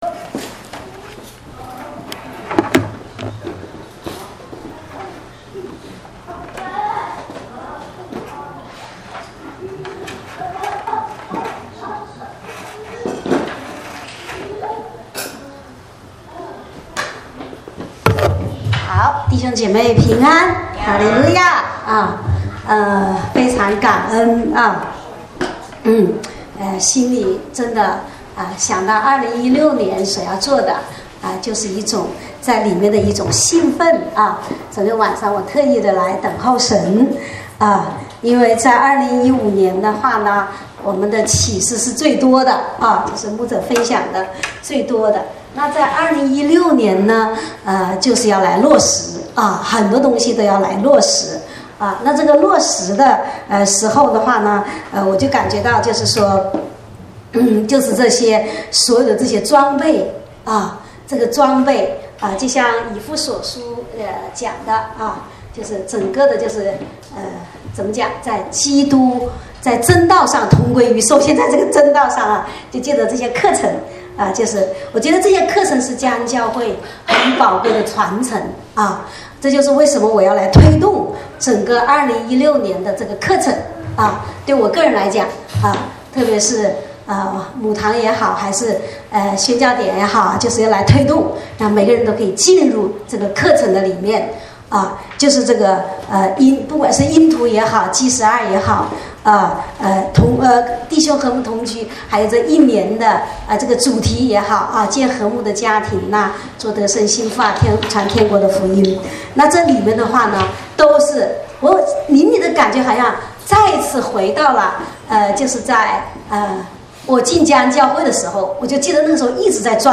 正在播放：--主日恩膏聚会录音（2016-01-10）